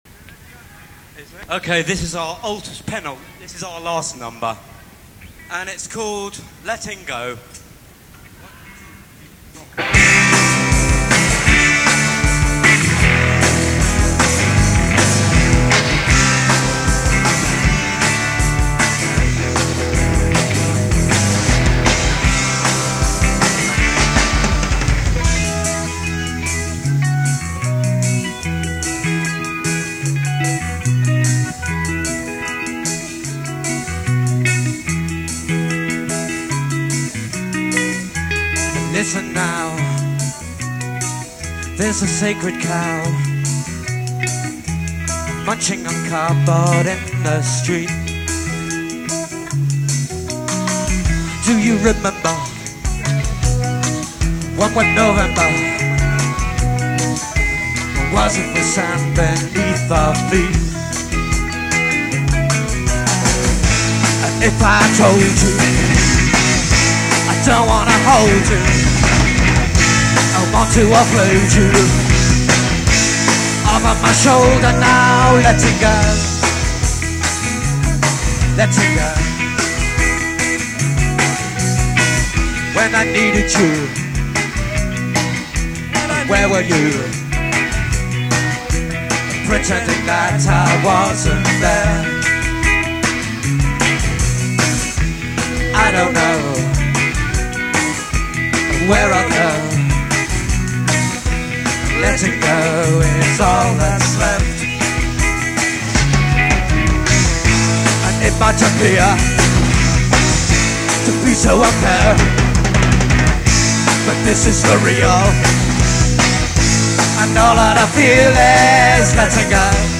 recorded live
guitar, vocal
bass
Highlights of three full-length (45min) gigs.
on drums and vocals, Totnes Civic Hall, January 1992: